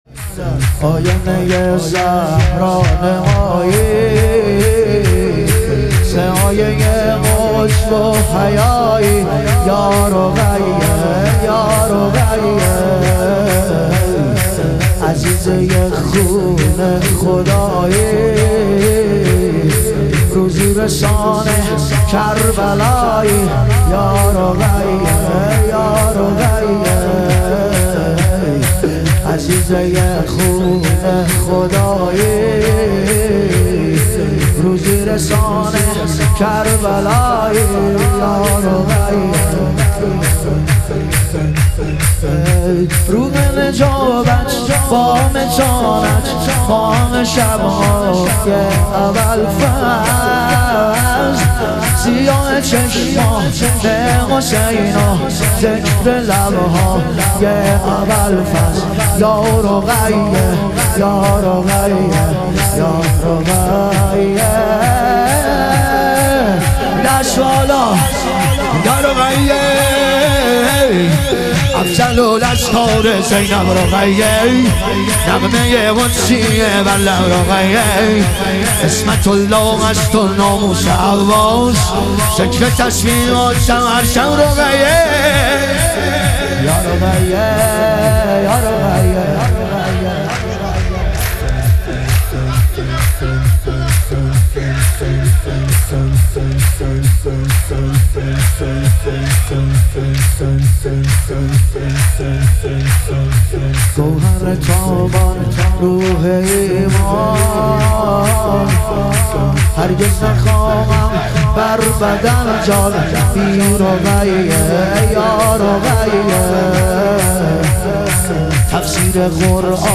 ظهور وجود مقدس حضرت رقیه علیها سلام - شور